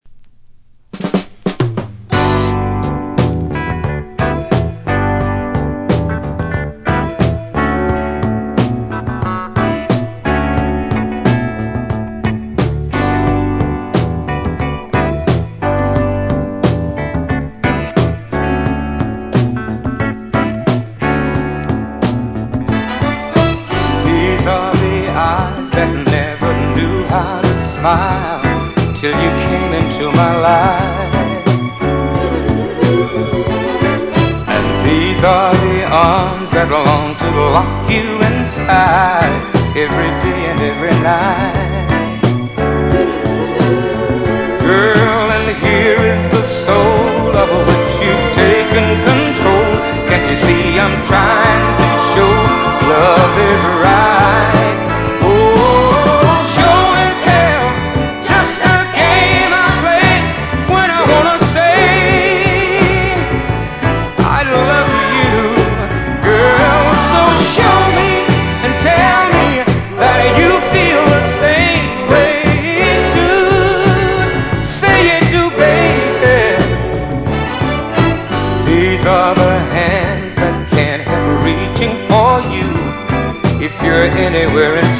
Genre: RARE SOUL